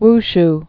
(wsh)